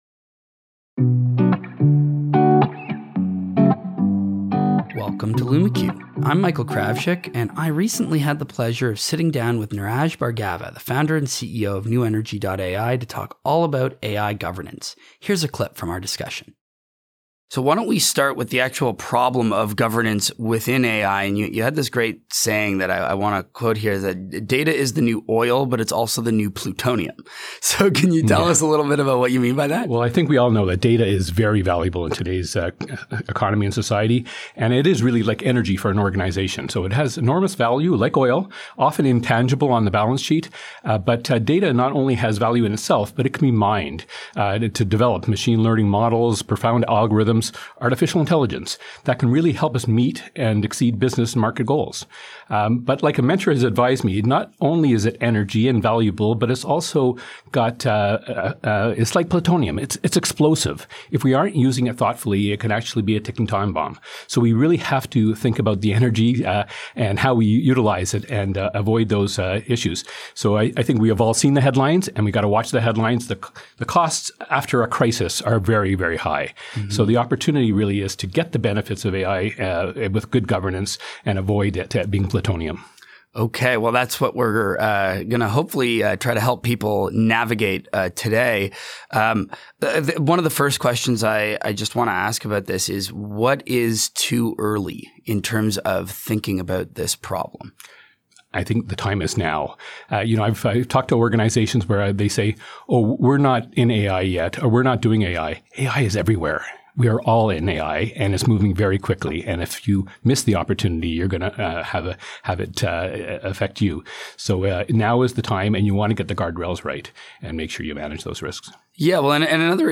• Managing the risks of AI (podcast excerpt)